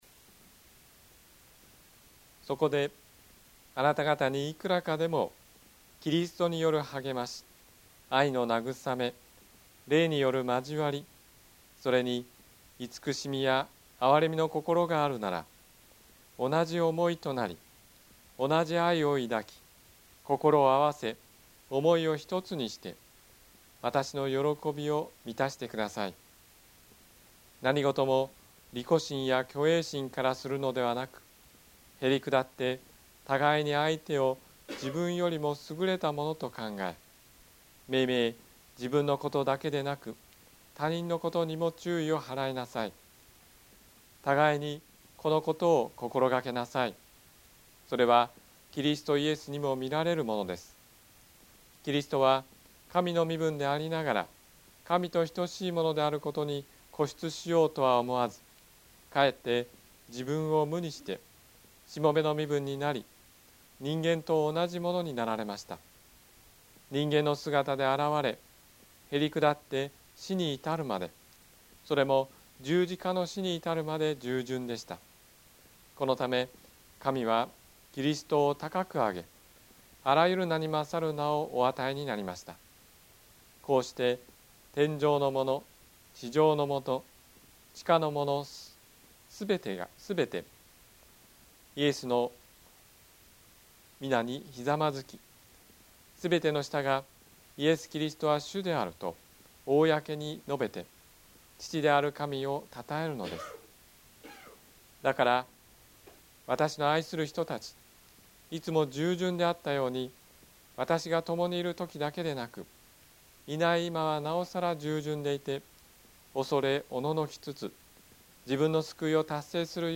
日曜 夕方の礼拝
説教